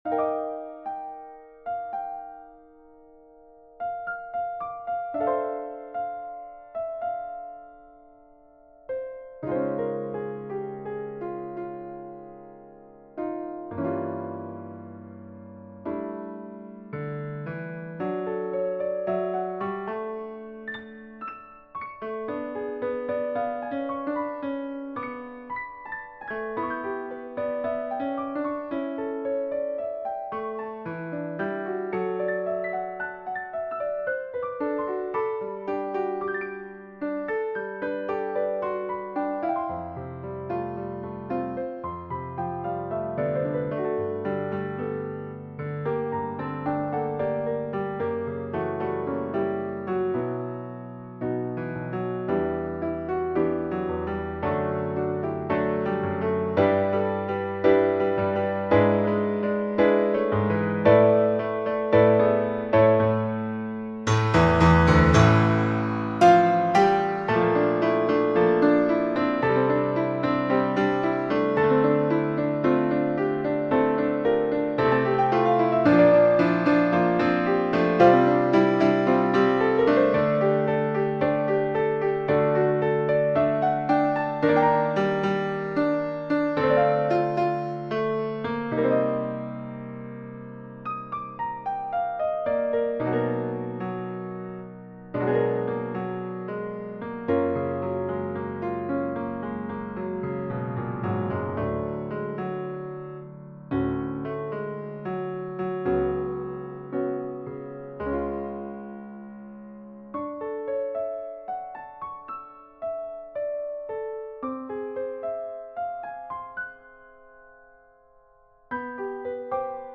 SAB mixed choir and piano
世俗音樂